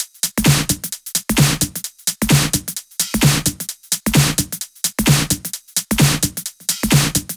VFH2 130BPM Comboocha Kit 6.wav